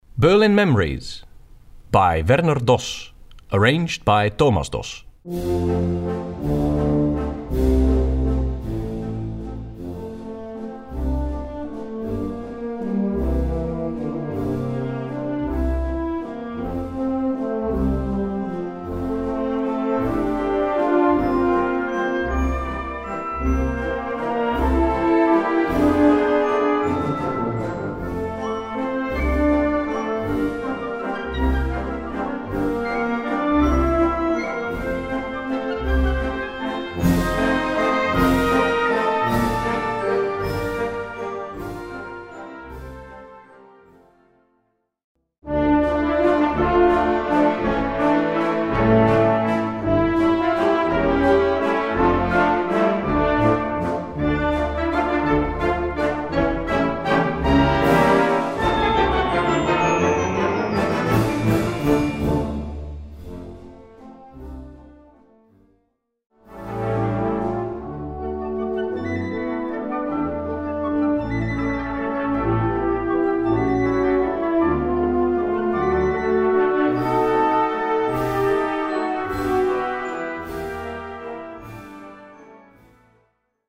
Gattung: Walzer
Besetzung: Blasorchester